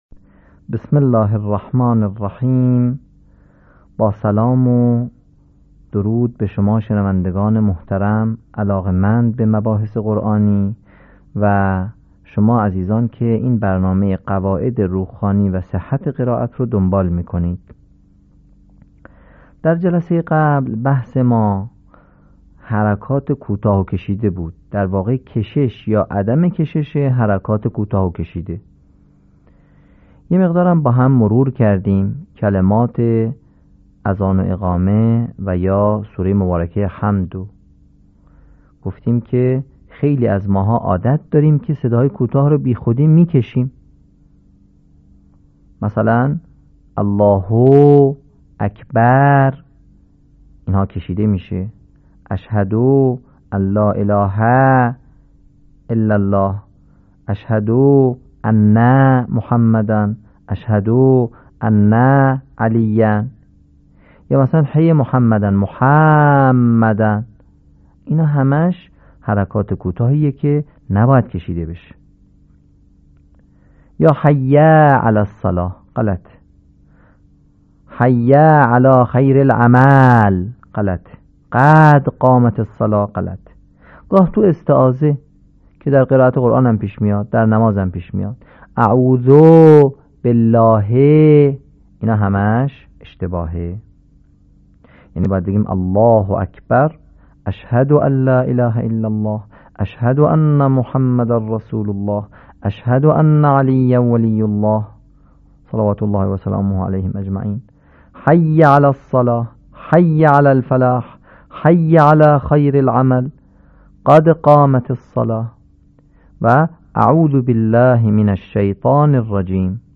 صوت | آموزش روخوانی «حروف اشباع و عدم اشباع»